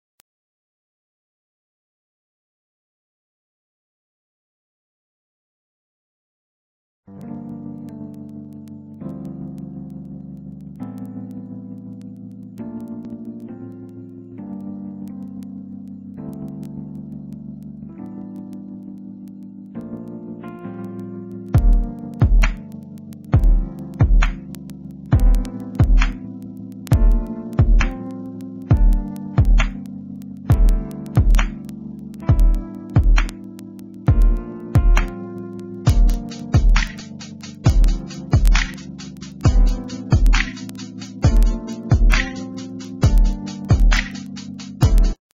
NOTE: Background Tracks 1 Thru 8